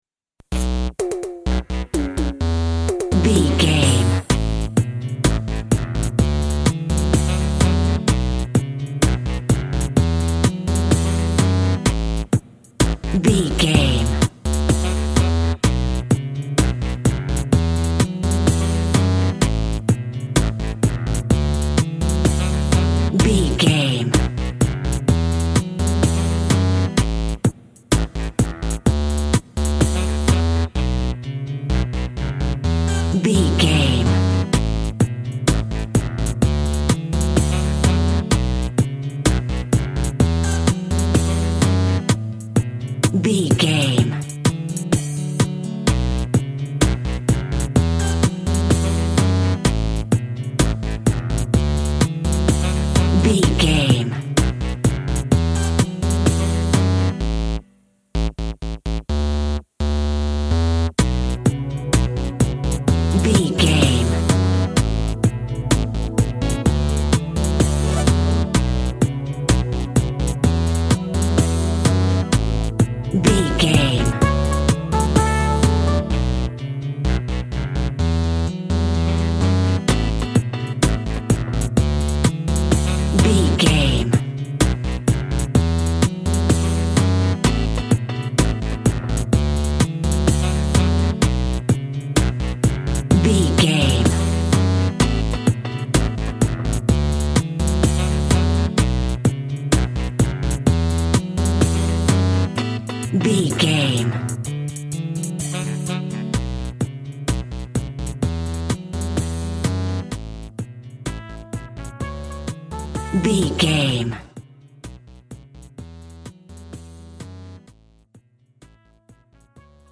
Also with small elements of Dub and Rasta music.
Uplifting
Aeolian/Minor
brass
pan pipes
steel drum